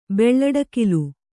♪ beḷḷaḍakilu